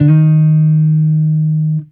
Guitar Slid Octave 04-D#2.wav